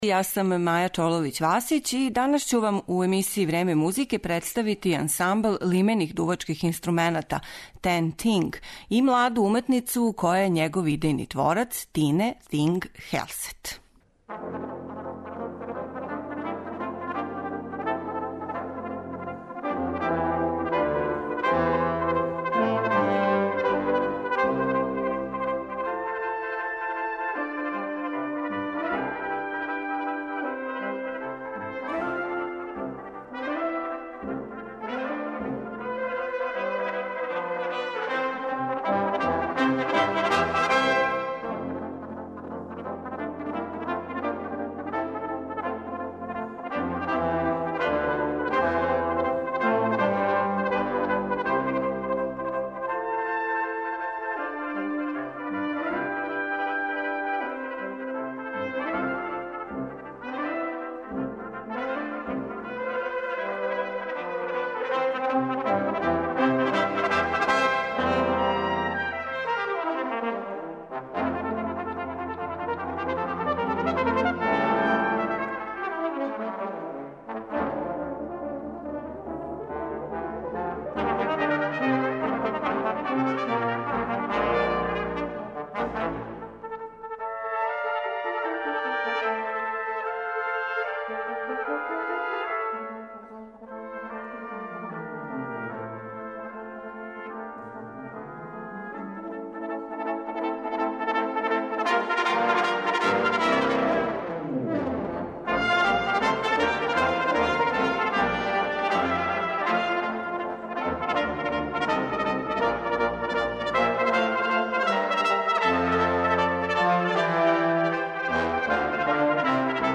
ансамблу лимених дувачких инструмената
виртуоз на труби.
Овај десеточлани, искључиво женски, ансамбл